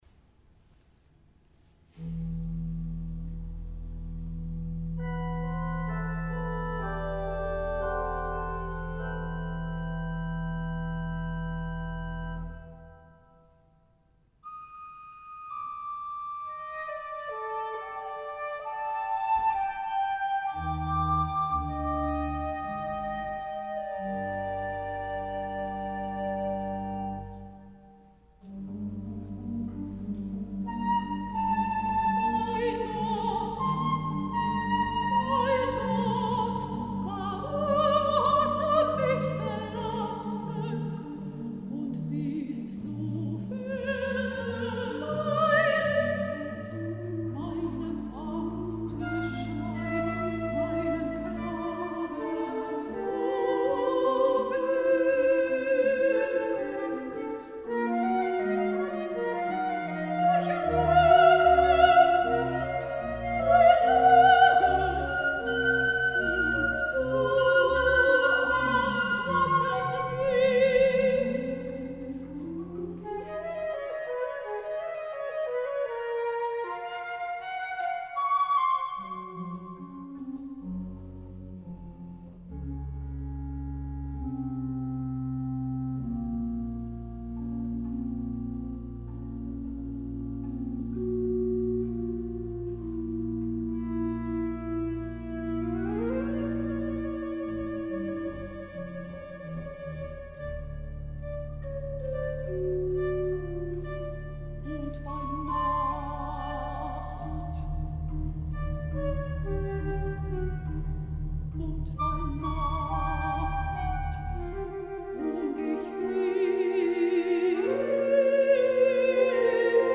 Trio della Passione di Gesu Christo für Sopran, Flöte und Orgel
Aufgenommen wurde am Palmsonntag des letzten Jahres, dem 9. April 2019 in einem Konzert in St. Josef.